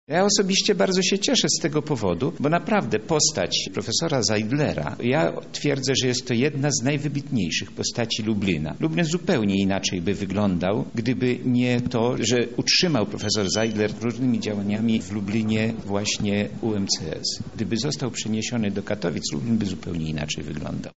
Nie mogę tej decyzji oceniać negatywnie – mówi radny Jan Gąbka.